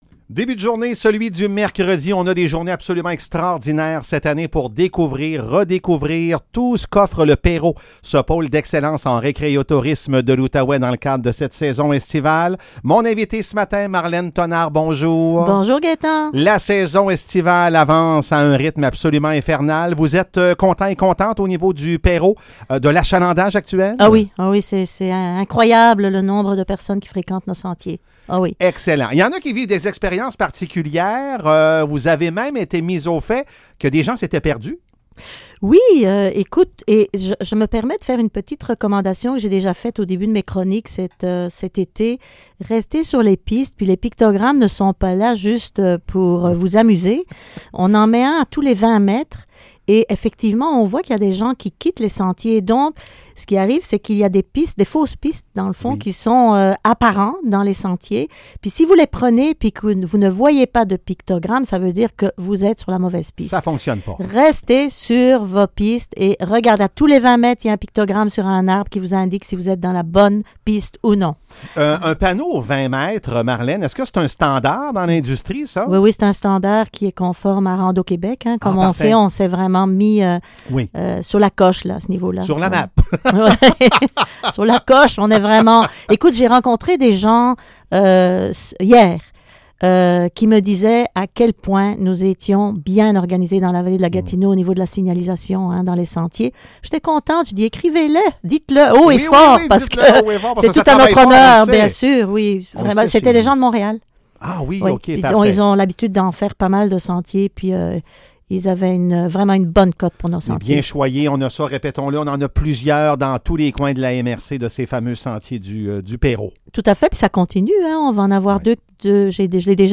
Chronique du PERO